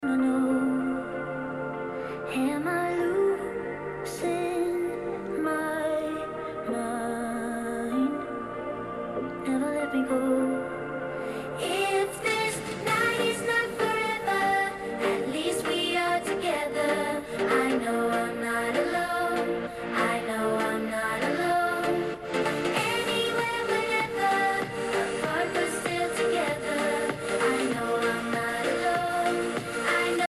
You won’t be greeted by an impacting force of bass that slaps your face.
The stereo speakers on the phone tune down their bass frequency, focusing purely on handling treble and mid-tune.
This setup provides the sound system of the ROG Phone 7 with a wider soundstage and better separation between frequencies.
With-Aeroactive-Cooler-7.mp3